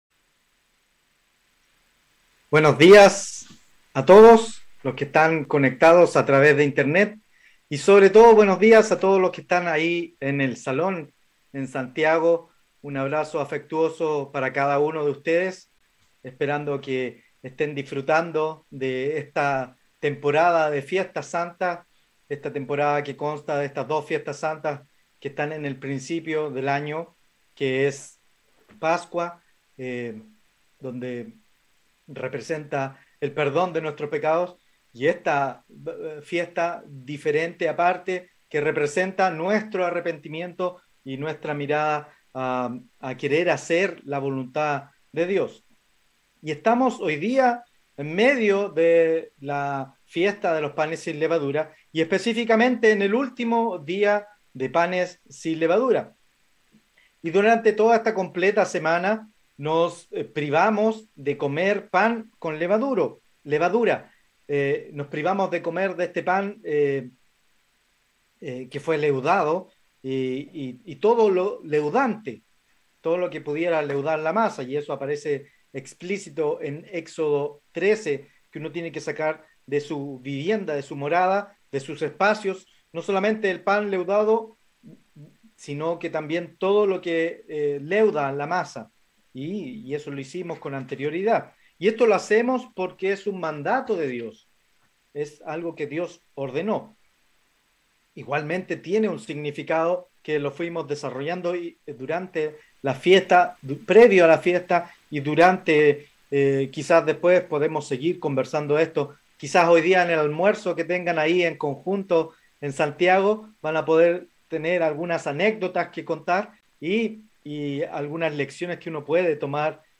Given in Temuco